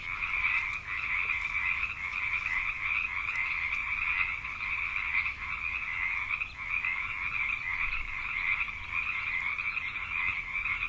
rwd-cache-z-labor.maps.at.rc3.world-sounds-frogs.wav-d19f2c2b.mp3